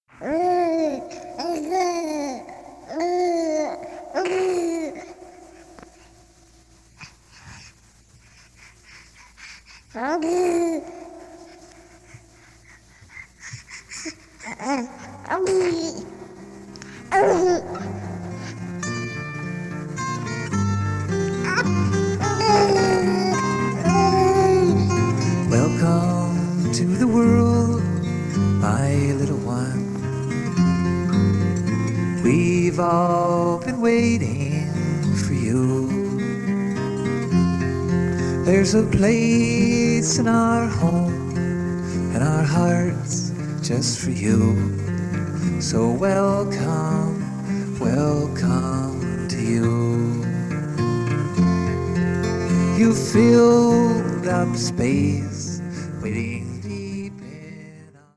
on an old Teac 4-track, reel-to-reel recorder.